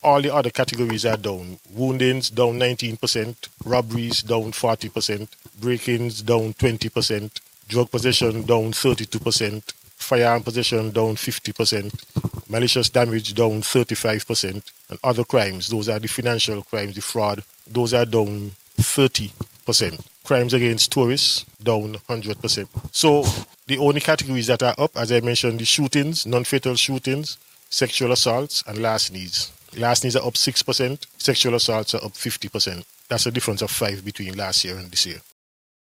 Deputy Commissioner of Police, Cromwell Henry.